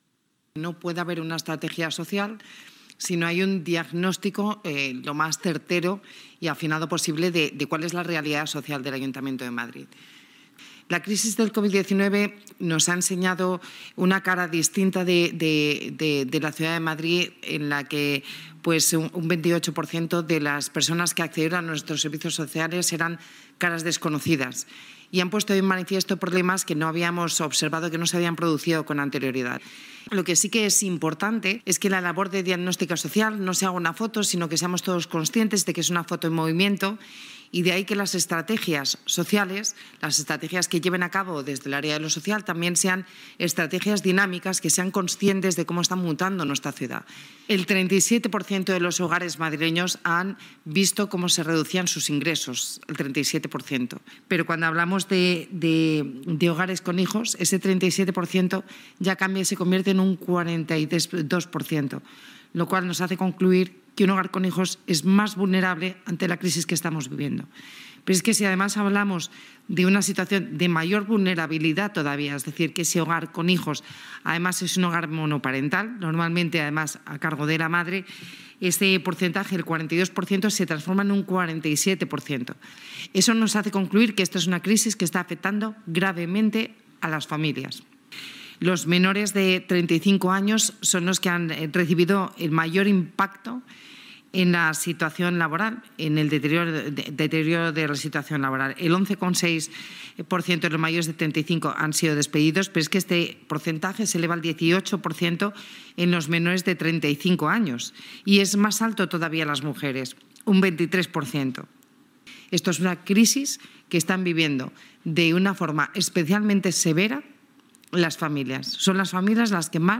Nueva ventana:Begoña Villacís señala algunos datos del informe y asegura que este diagnóstico servirá de guía para diseñar las estrategias de servicios sociales en Madrid